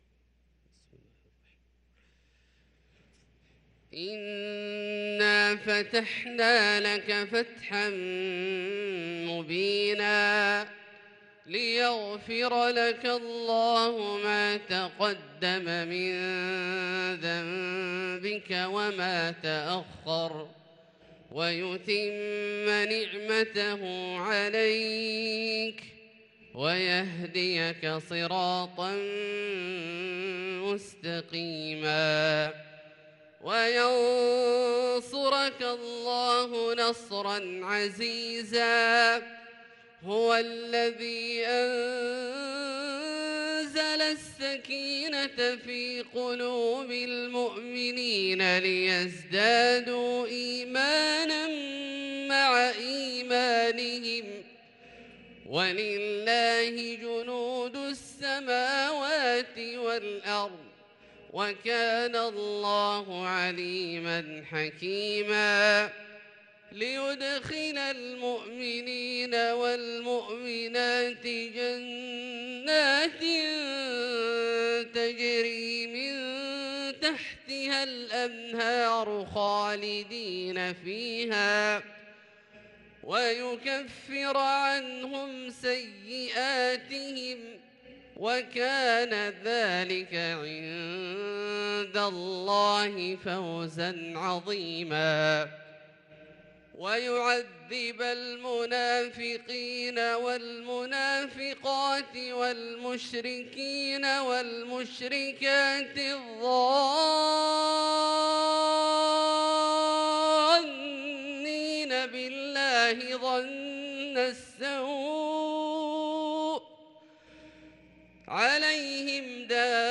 سورة الفتح كاملة للشيخ عبدالله الجهني مجمعة من فجريات شهر ذو الحجة 1443هـ > السور المكتملة للشيخ عبدالله الجهني من الحرم المكي 🕋 > السور المكتملة 🕋 > المزيد - تلاوات الحرمين